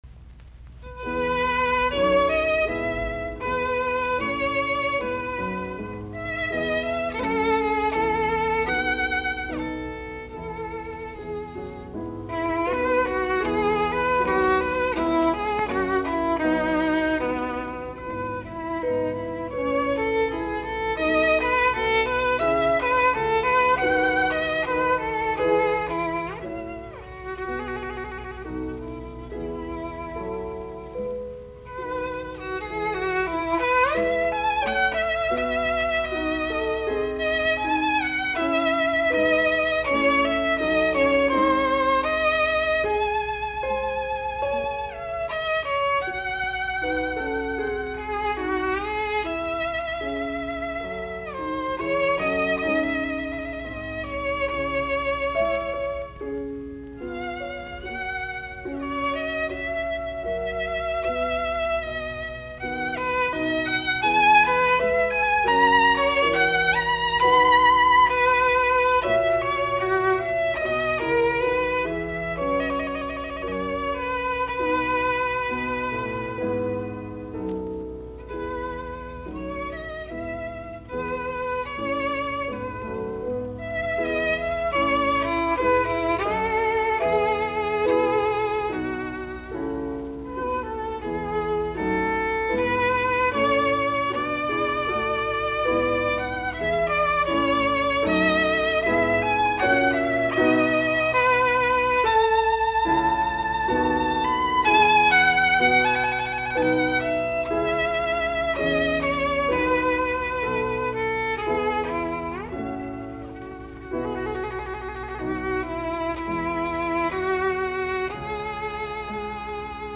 sov. viulu, piano
Soitinnus: Viulu, piano.